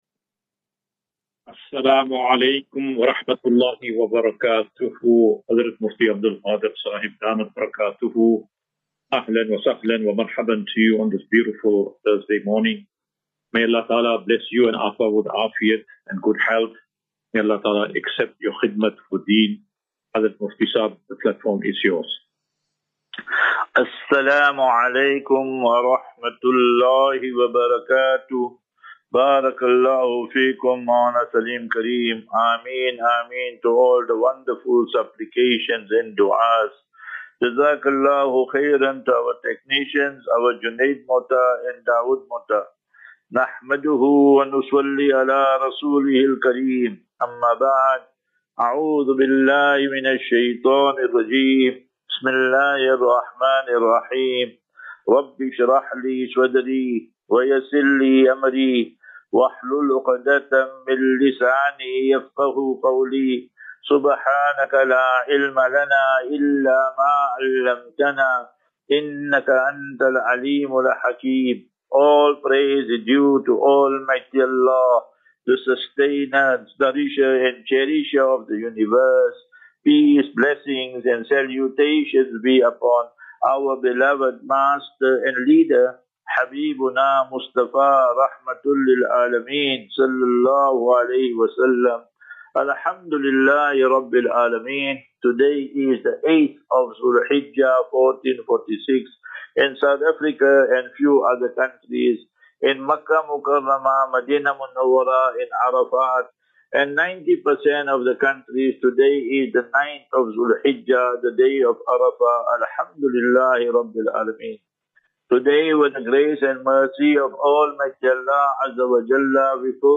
5 Jun 05 June 2025. Assafinatu - Illal - Jannah. QnA.